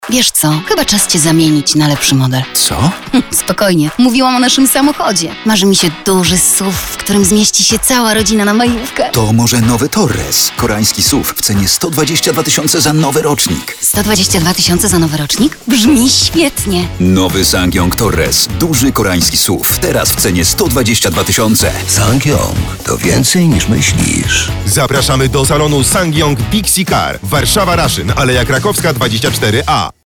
Nowy Torres '2025 na Majówkę od 122 700 zł (Reklama)